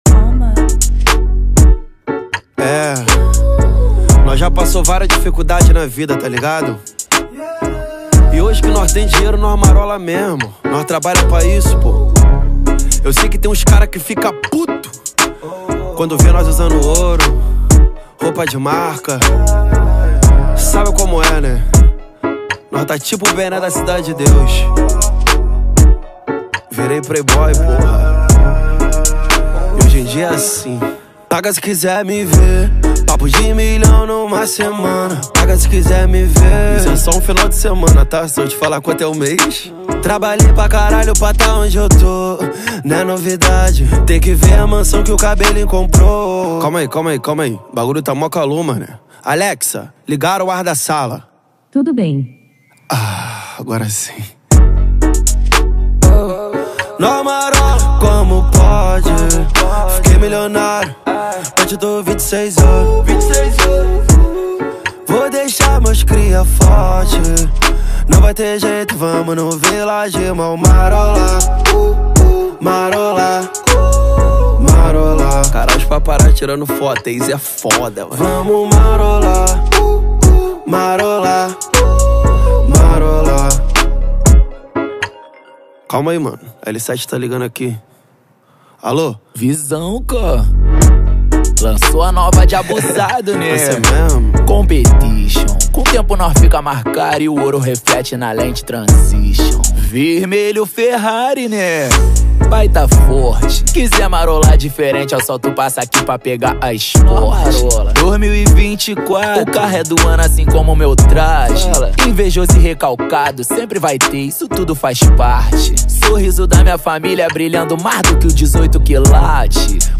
2024-07-17 11:01:12 Gênero: Trap Views